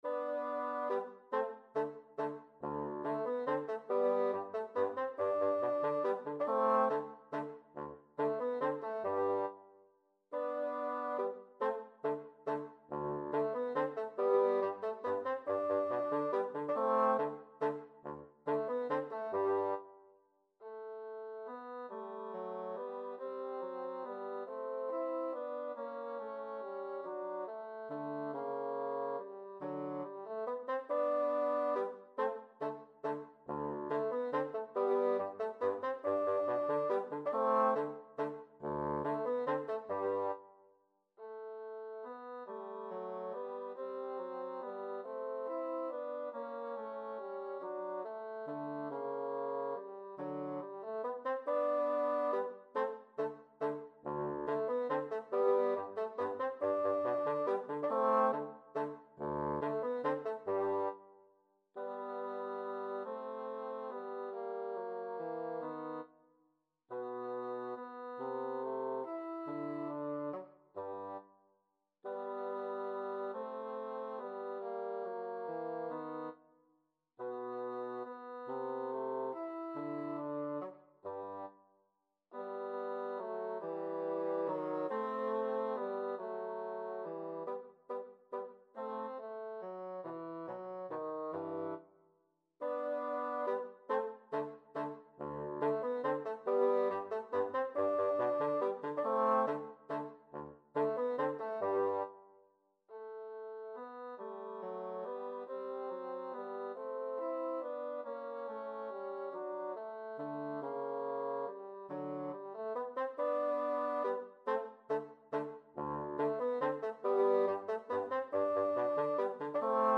G major (Sounding Pitch) (View more G major Music for Bassoon Duet )
3/4 (View more 3/4 Music)
Allegretto - Menuetto =140
Bassoon Duet  (View more Easy Bassoon Duet Music)
Classical (View more Classical Bassoon Duet Music)
mozart_k487_no2_2BN.mp3